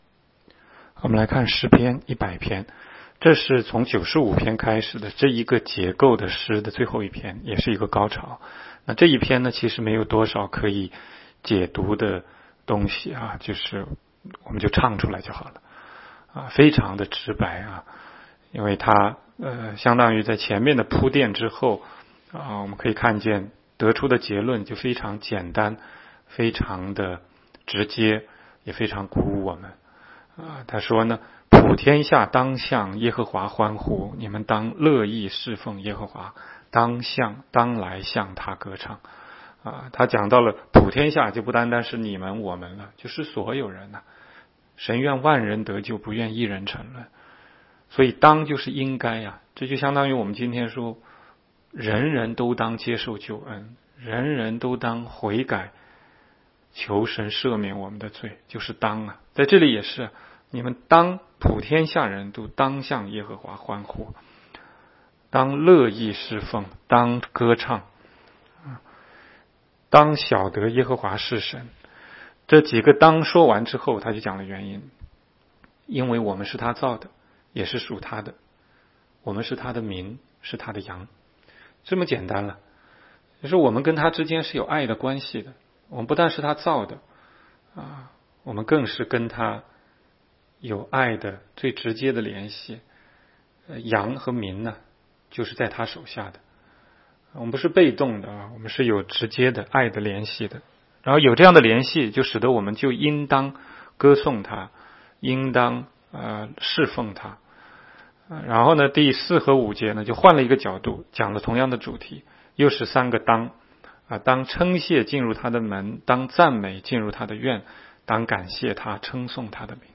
16街讲道录音 - 每日读经-《诗篇》100章